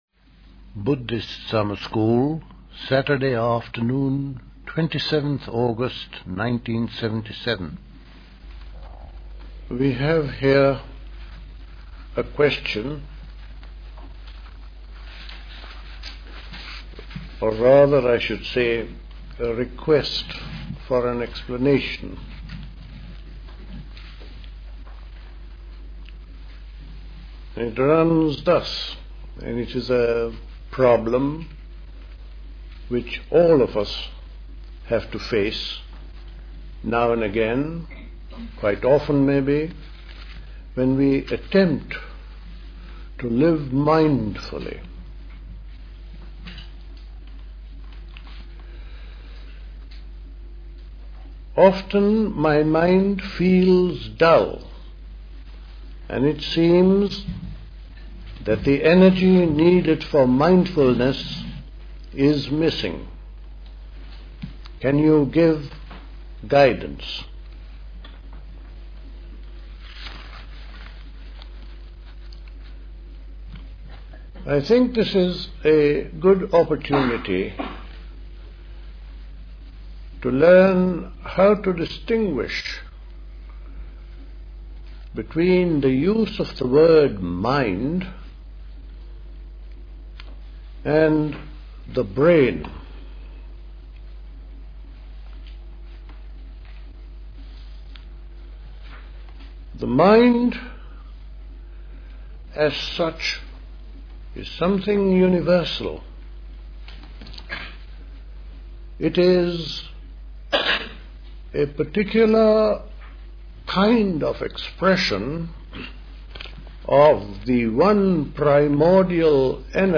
Play Talk
The Buddhist Society Summer School Talks